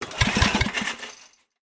in_generator_pull_02_hpx
Electric generator starts and sputters then shuts off. Electric Generator Motor, Generator Engine, Generator